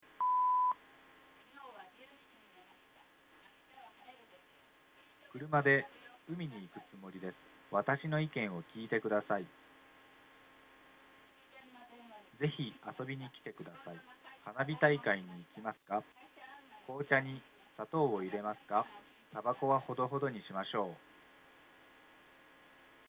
PattXX_L女性R男性_ECON_SCON PCステレオ信号出力のLch女声、Rch男声の組み合わせです。
”Patt03_L女性R男性_ECON_SCON.mp3”にくらべ、リセット直後から女声のエコーが小さいです。
Patt04_L女性R男性_ECON_SCON.mp3